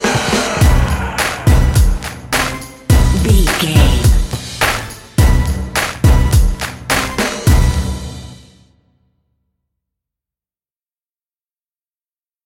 Aeolian/Minor
drum machine
synthesiser